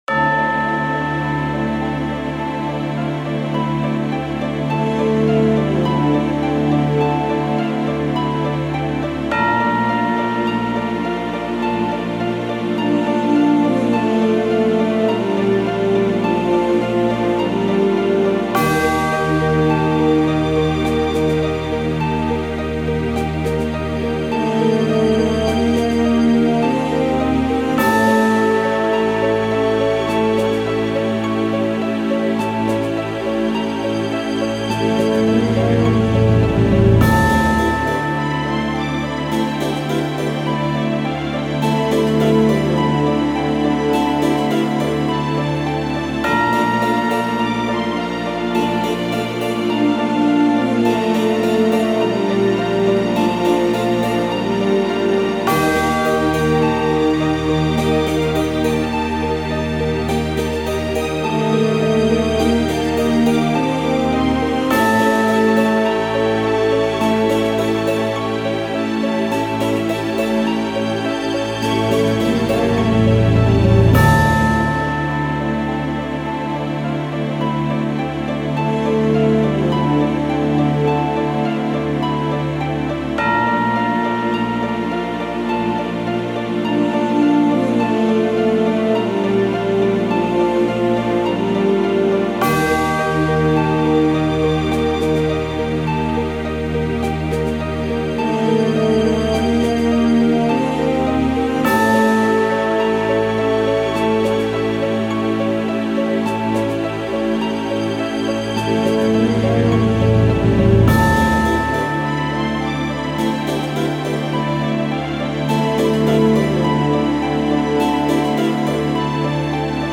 イメージ：タイトル 重い   カテゴリ：RPG−テーマ・序盤